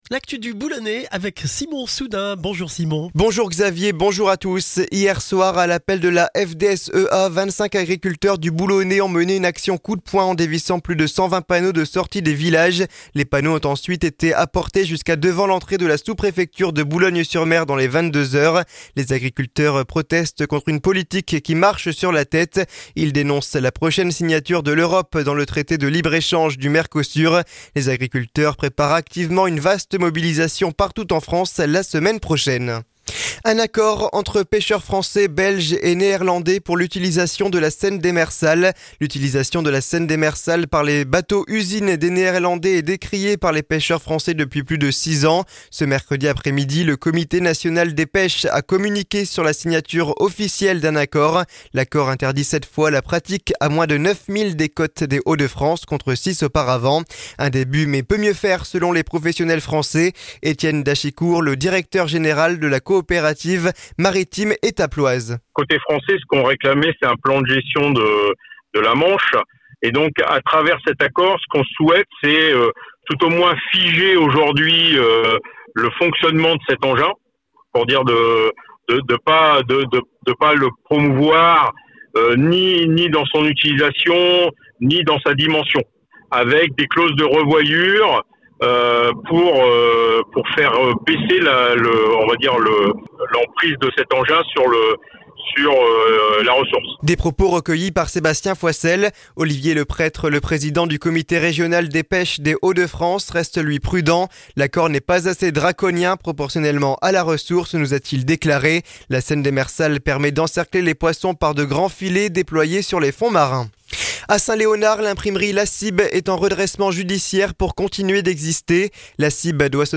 Le journal du jeudi 14 novembre dans le Boulonnais